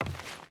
Footsteps / Wood
Wood Walk 1.wav